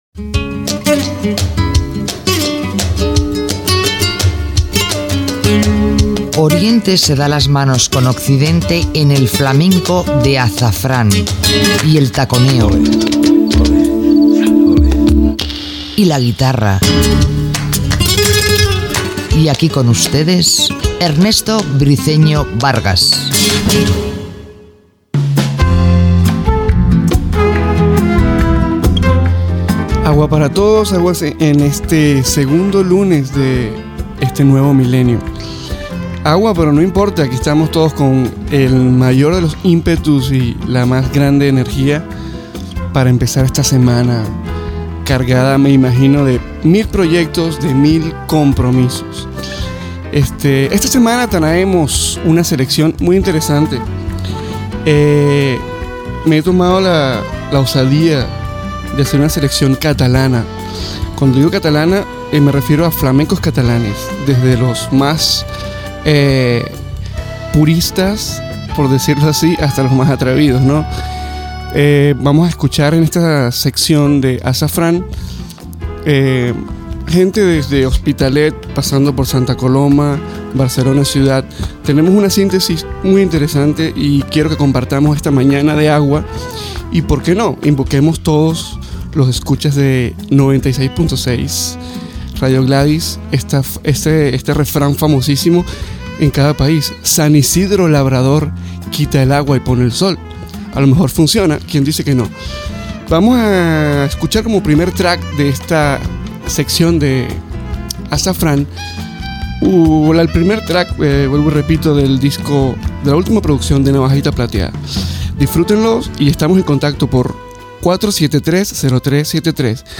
Careta del programa, presentació de l'espai dedicat als flamencs catalans i tema musical
Musical
FM